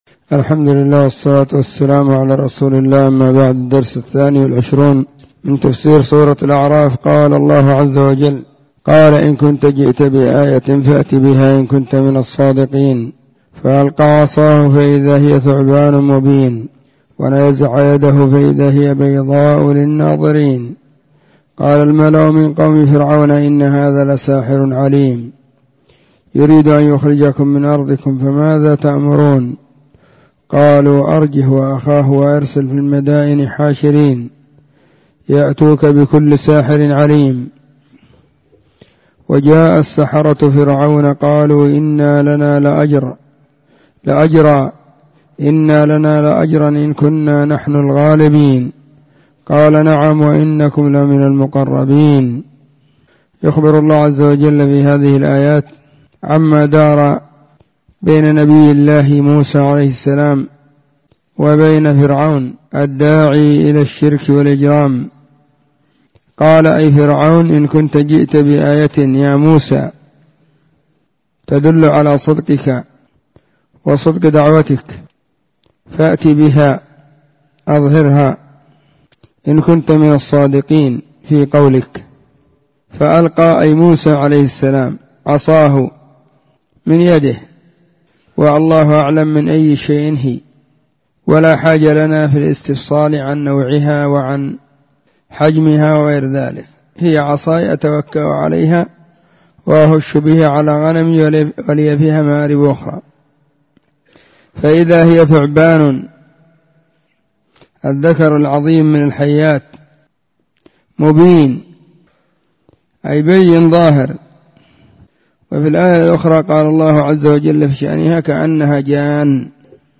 تفسير سورة الأعراف: الدرس 22
📢 مسجد الصحابة – بالغيضة – المهرة، اليمن حرسها الله.